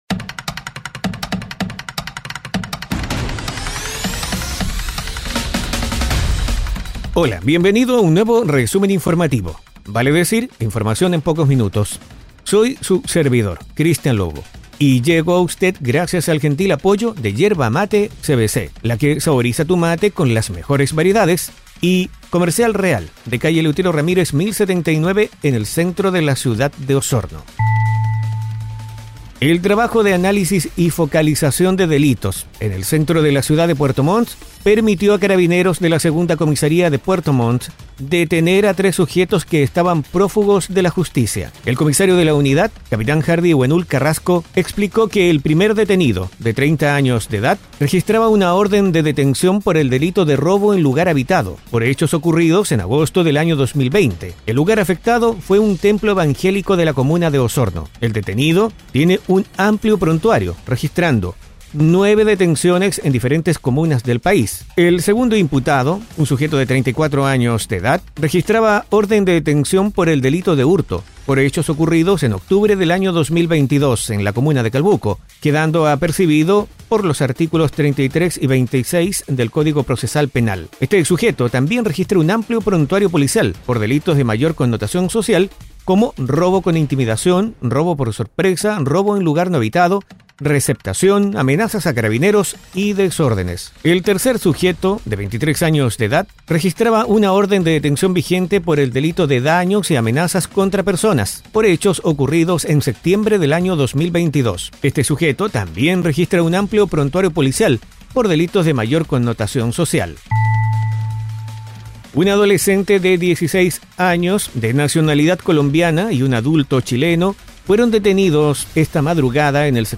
Resumen Informativo 🎙 Podcast 13 de enero de 2023